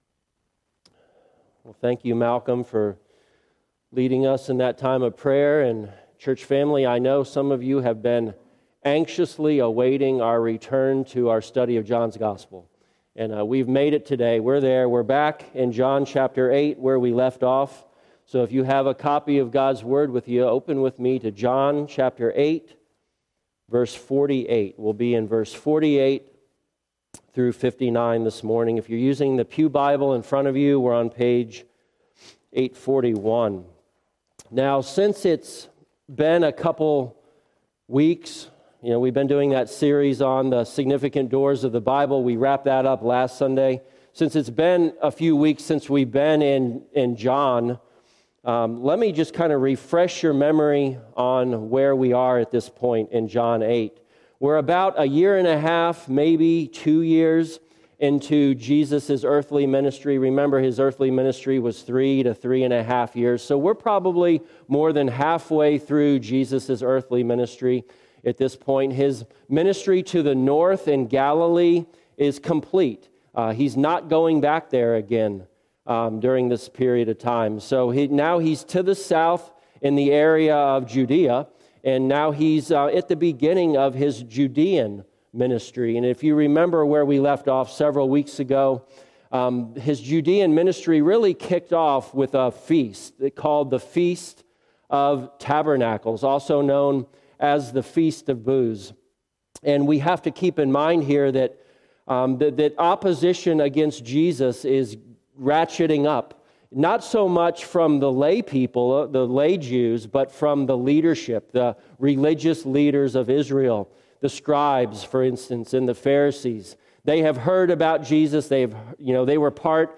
Passage: John 8:48-59 Service Type: Sunday Morning « Jesus